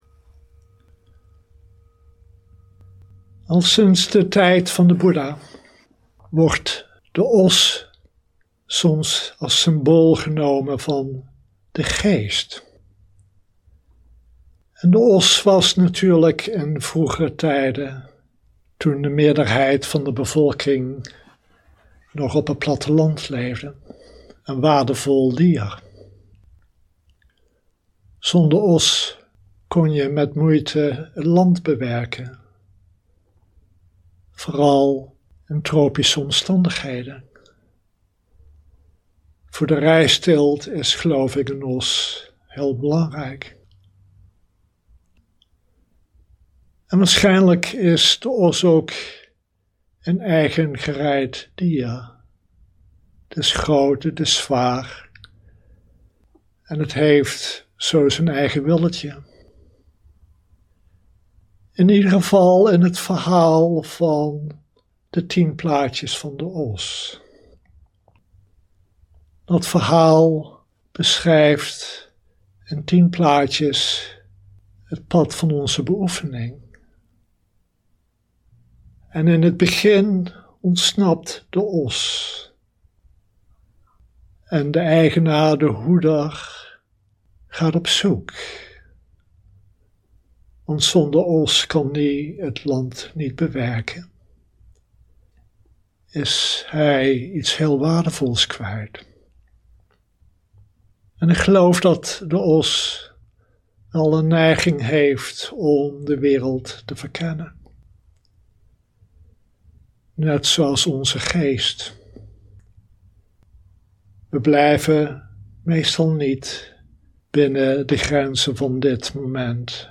Dharma-onderwijs
Livestream opname